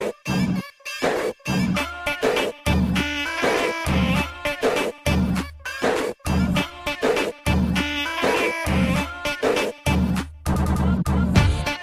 الأقسام : Electronica
تسميات : dubstep robot screaming itchy